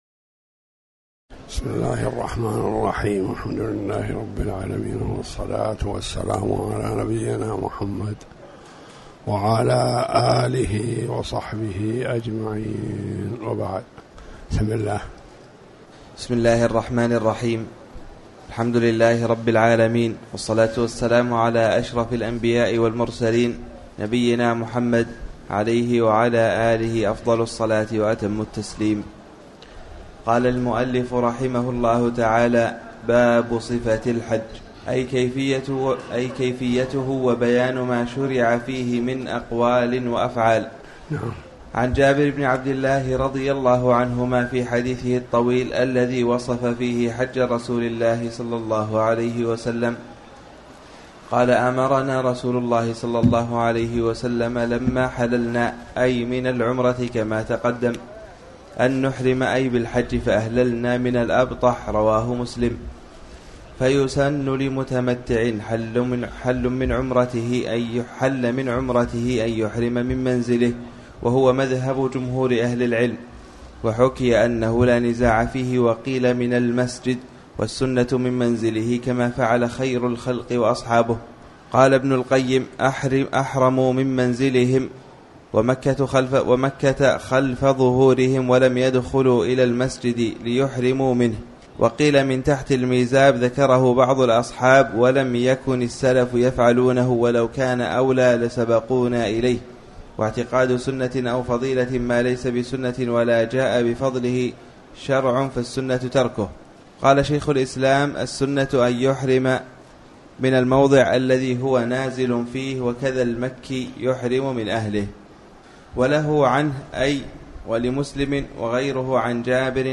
تاريخ النشر ١٩ محرم ١٤٤٠ هـ المكان: المسجد الحرام الشيخ